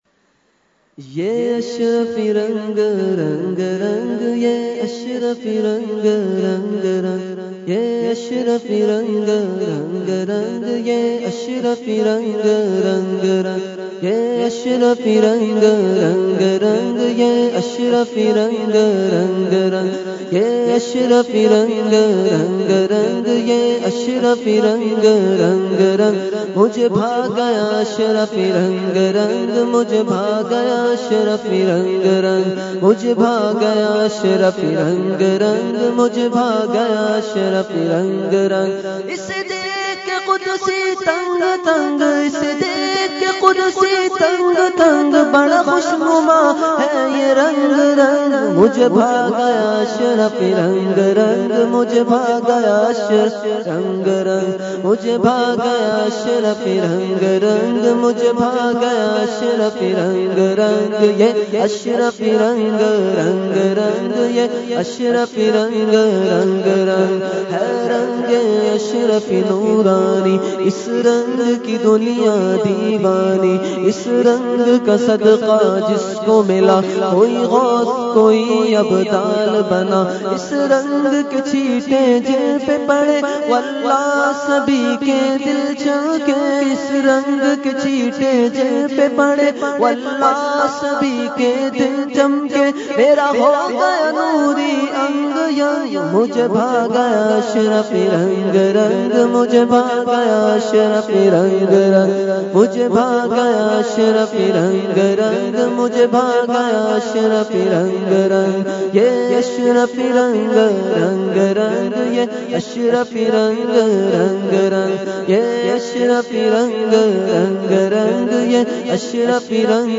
Category : Manqabat | Language : UrduEvent : Urs Makhdoome Samnani 2018